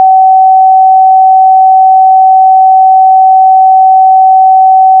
I wrote a script that loads the video, pulls out the audio, does a fourier transform on a number of segments, and then plots the frequency spectrum of each segment.1 If you do this with a singing segment, you get a clear peak at 758-760 Hz (call it 759 Hz).
You can play this frequency using an online tool, or just by using python to create a tone for you:1